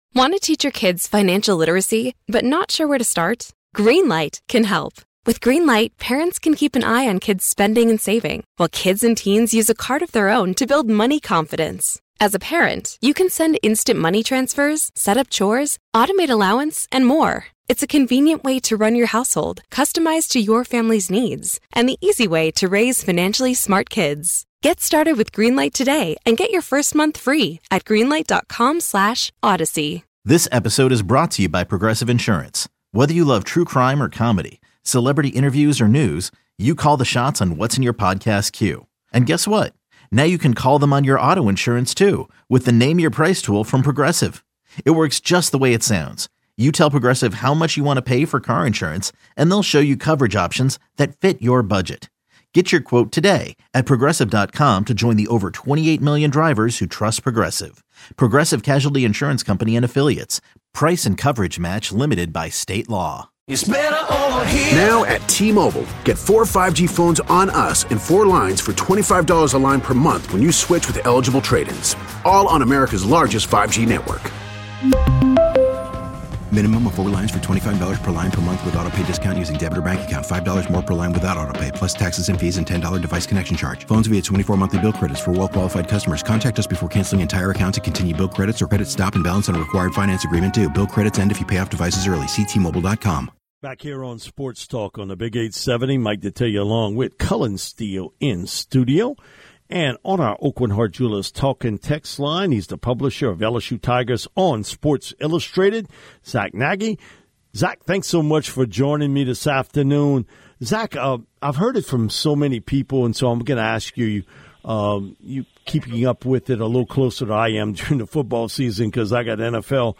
LSU interviews, press conferences and more